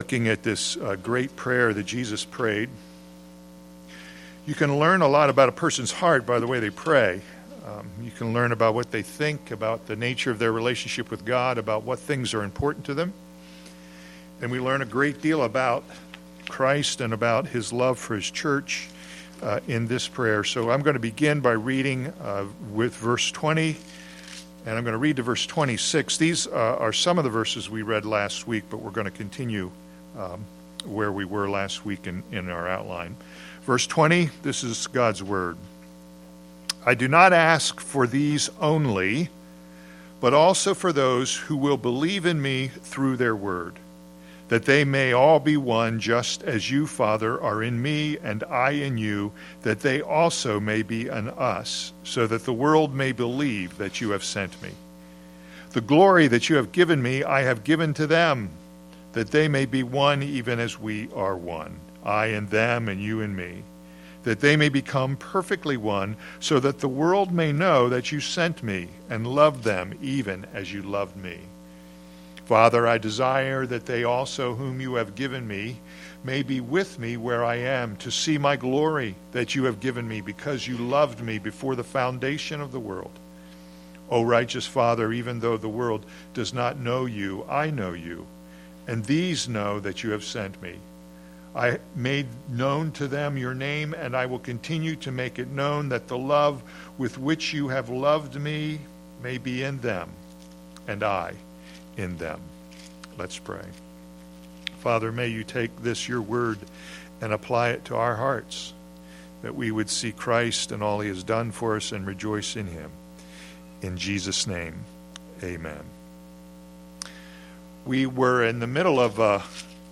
All Sermons The Lord’s Prayer for His Church